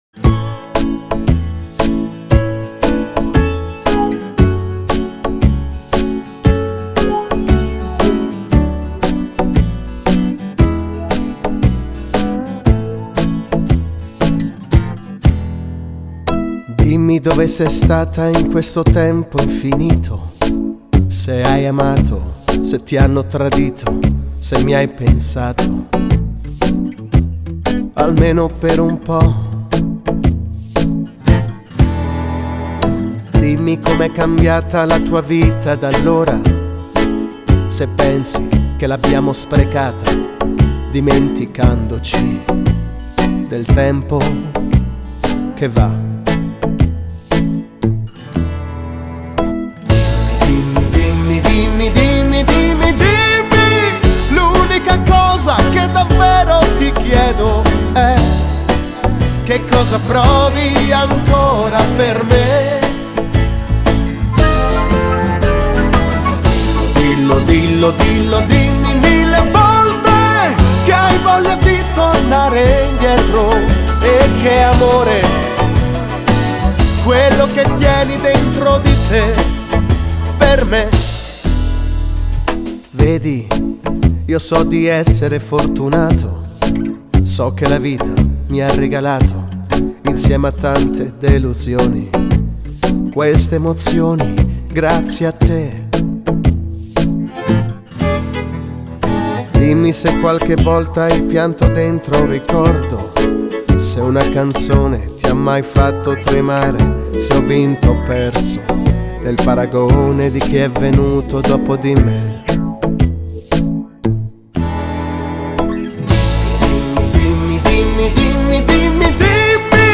Slow-Fox
Canzone pop, ballabile a Slow-Fox.